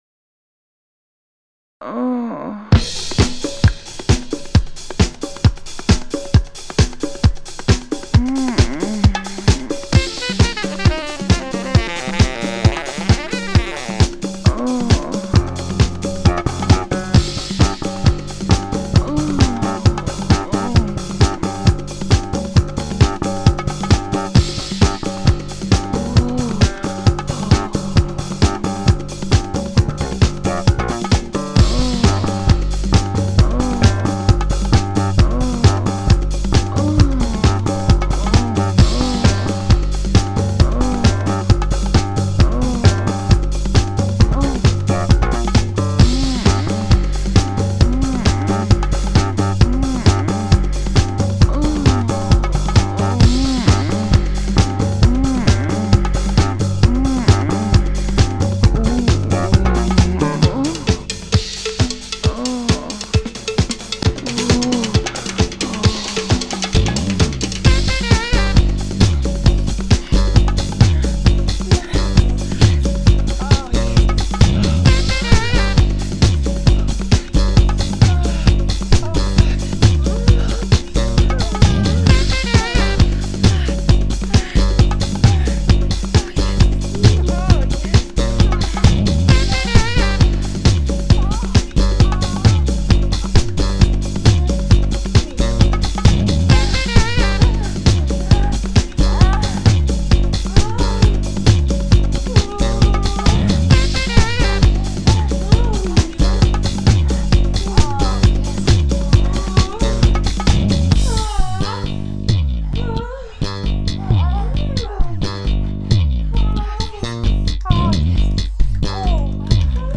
A nice little samba tune about the usual topics.